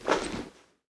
equip_leather2.ogg